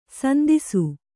♪ sandisu